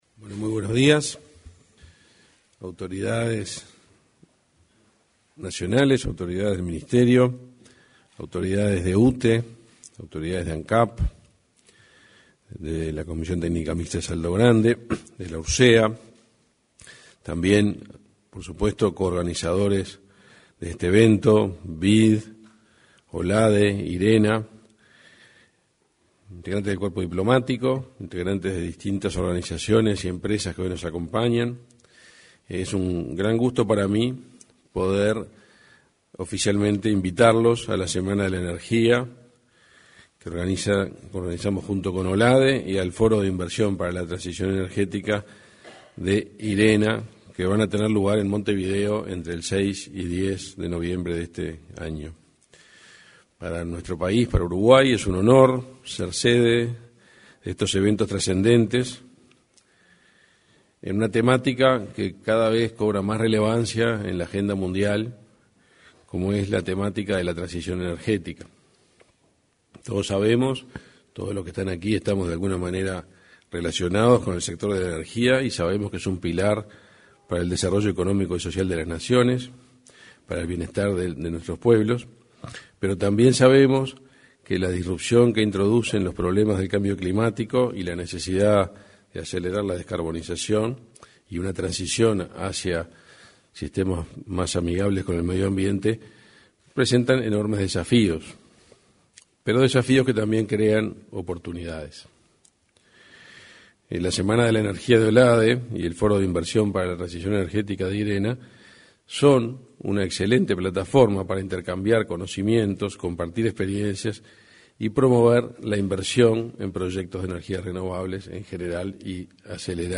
Palabras del ministro de Industria, Energía y Minería, Omar Paganini
Palabras del ministro de Industria, Energía y Minería, Omar Paganini 17/08/2023 Compartir Facebook X Copiar enlace WhatsApp LinkedIn En el marco del lanzamiento de la VIII Semana de la Energía, realizado este jueves 17 en el auditorio de la Torre Ejecutiva anexa, se expresó el ministro de Industria, Energía y Minería, Omar Paganini.